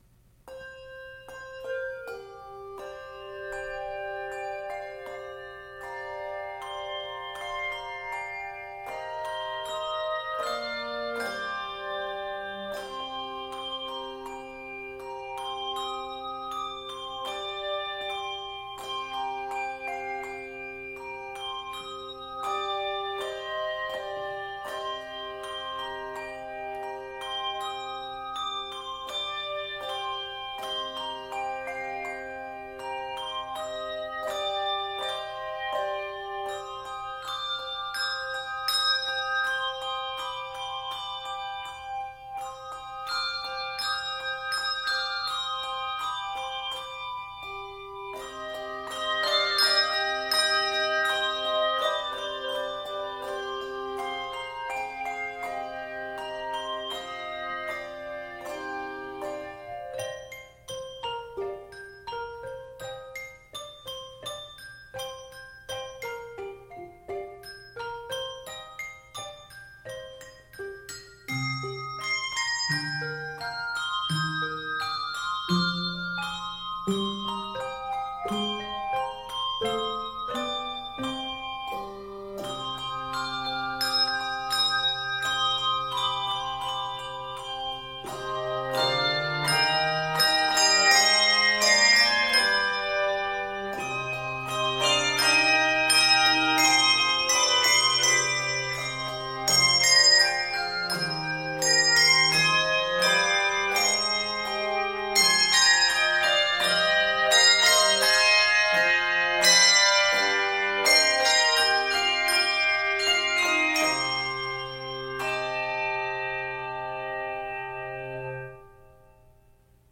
Octaves: 3-5